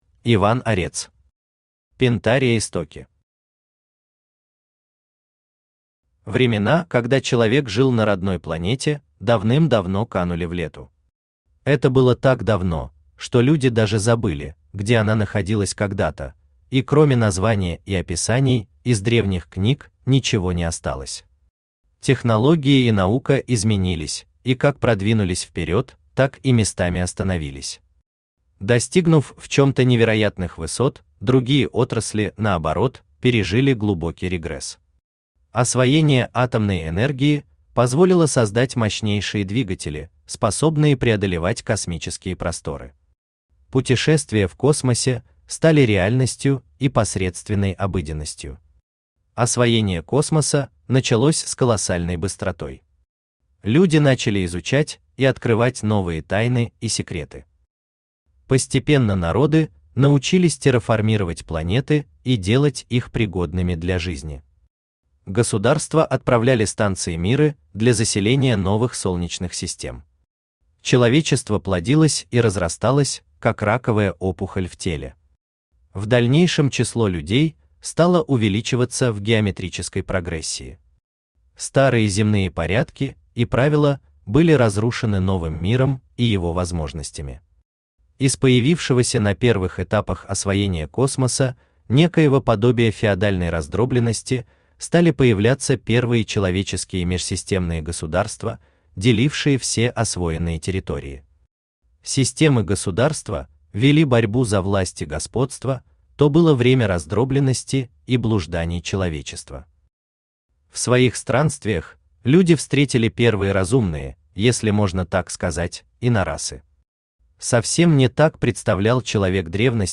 Аудиокнига Пентария «Истоки» | Библиотека аудиокниг
Aудиокнига Пентария «Истоки» Автор Иван Орец Читает аудиокнигу Авточтец ЛитРес.